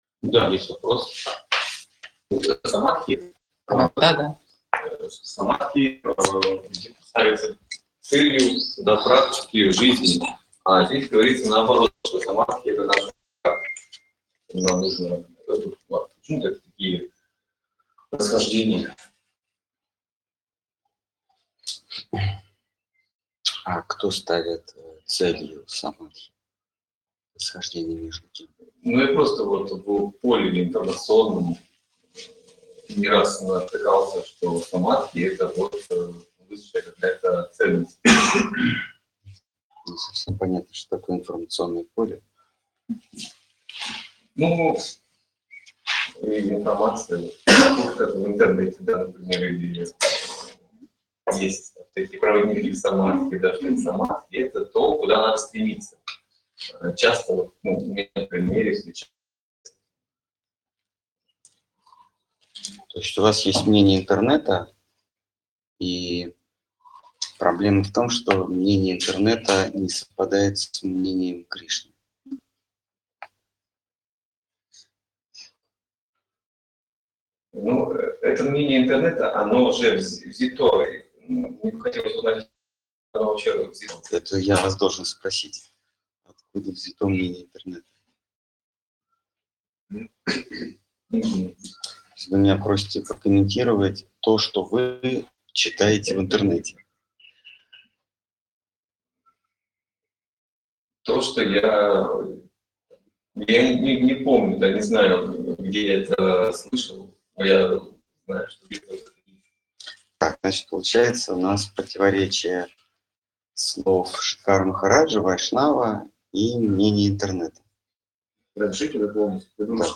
Ответы на вопросы из трансляции в телеграм канале «Колесница Джаганнатха». Тема трансляции: Слово Хранителя Преданности.